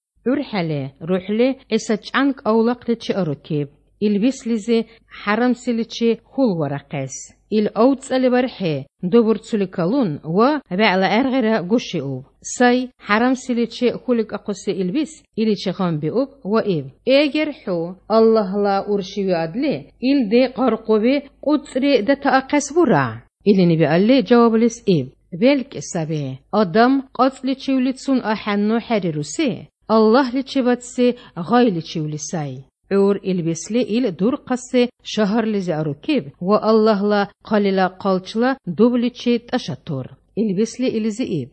17 August 2015 at 11:28 am Yes, some apparent Arabic or similar influences there, at least a couple of Semitic-sounding gutturals and possibly some loanwords, but the language itself is of different stock.
I find this language very pleasant-sounding, by the way.